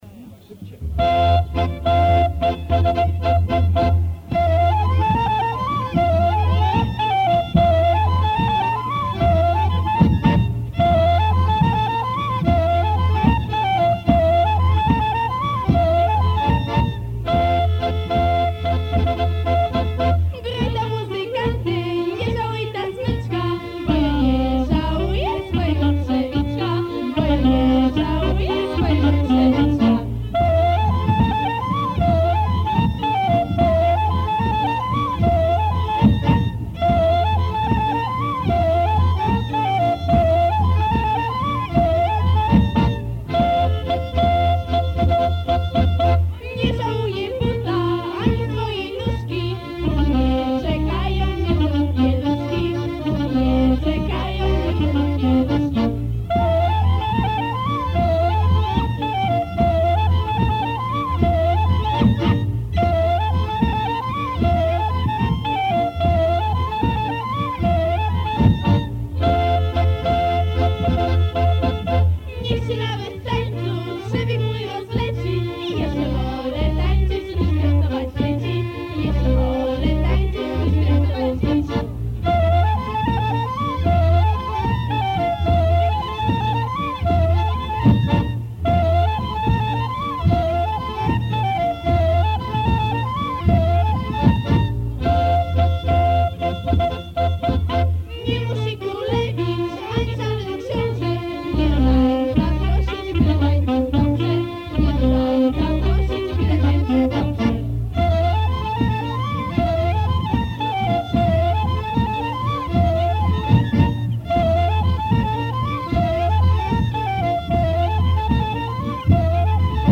Dix septième pièce - Kapella avec voix féminines